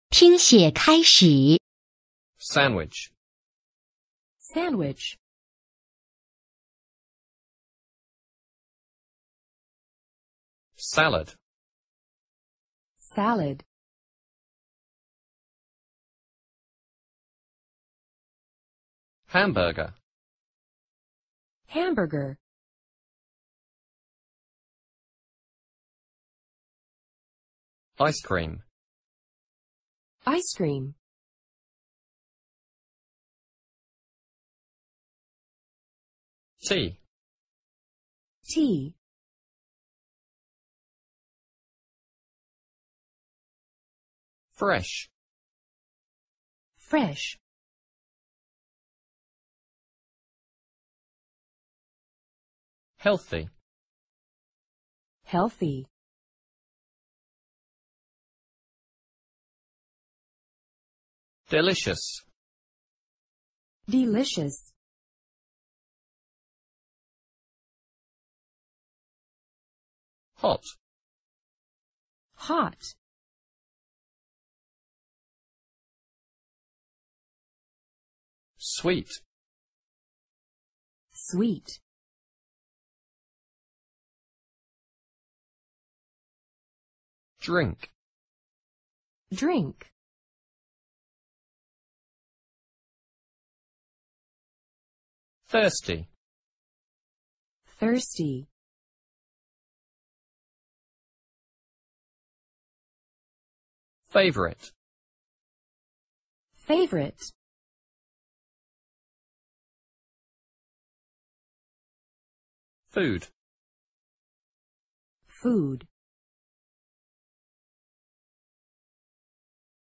Unit 3 单词【听写】（人教版PEP五年级上册）